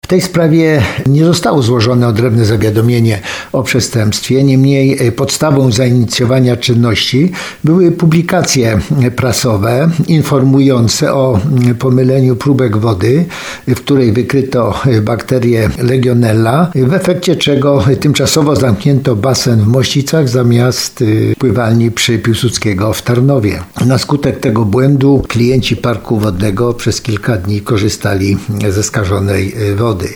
Jak poinformował rzecznik prasowy Prokuratury Okręgowej w Tarnowie prokurator Mieczysław Sienicki, czynności zostały podjęte z urzędu.